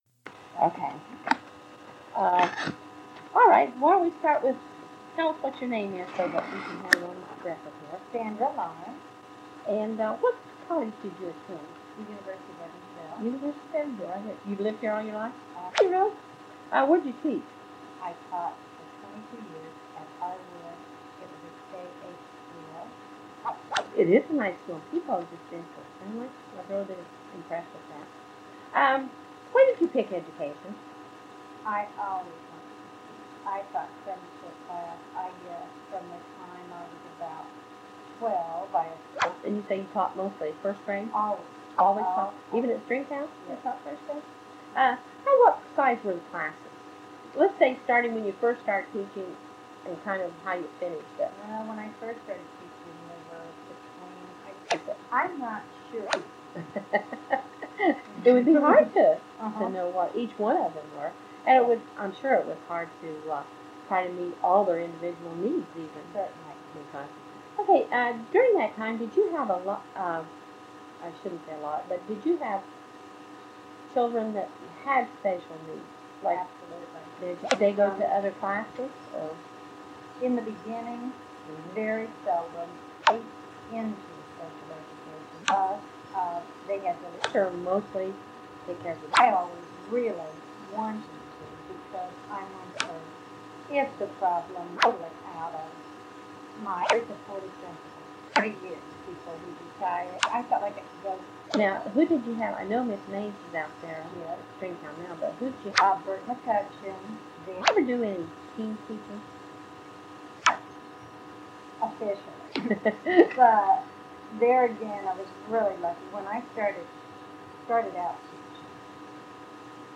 Evansville, Indiana